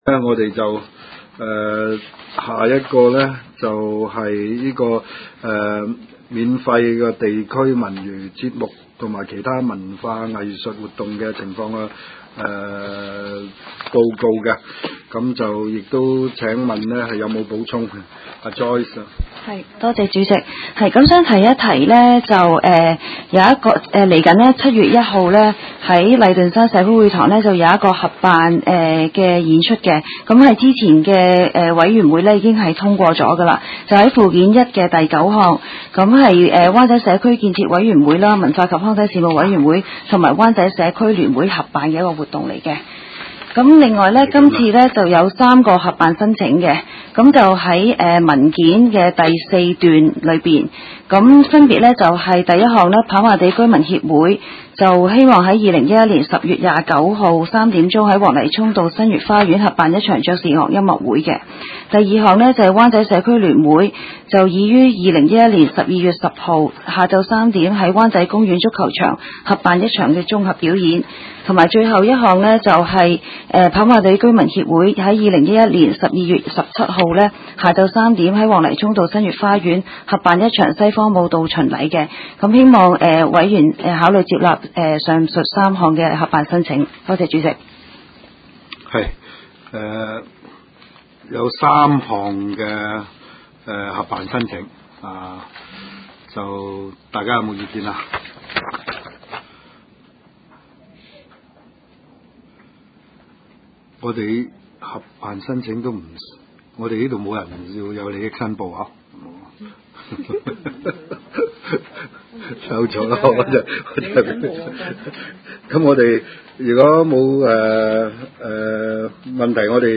灣仔民政事務處區議會會議室